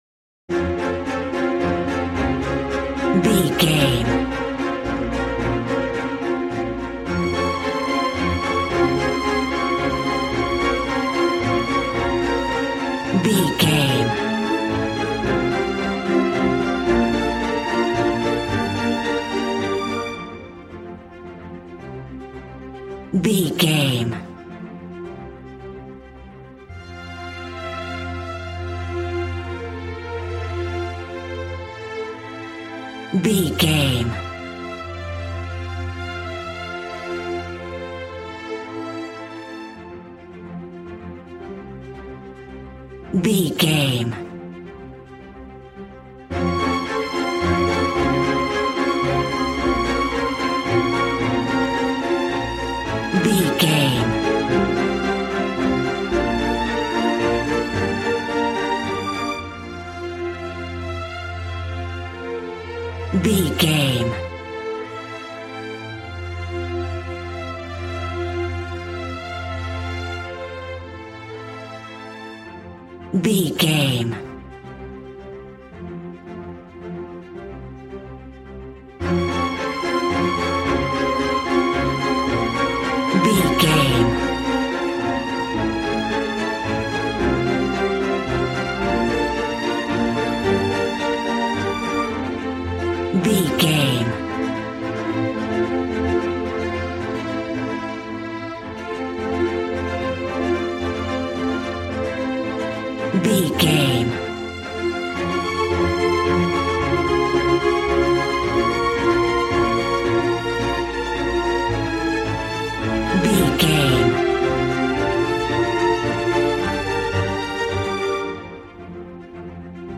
A classical music mood from the orchestra.
Regal and romantic, a classy piece of classical music.
Ionian/Major
regal
cello
violin
strings